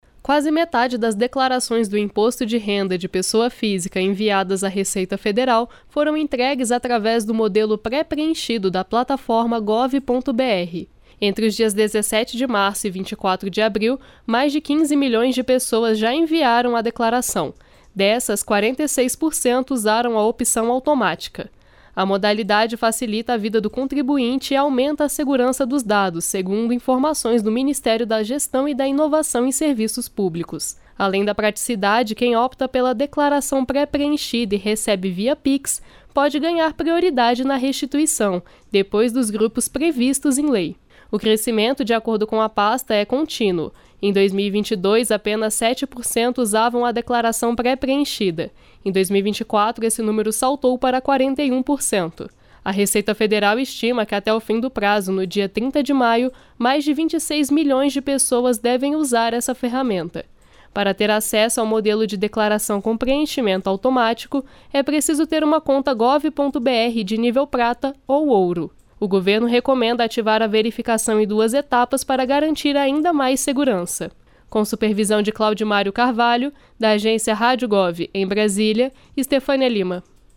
30/04/24 - Pronunciamento do Ministro do Trabalho e Emprego Luiz Marinho